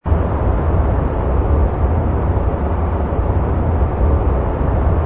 ambience_equip_ground_larger.wav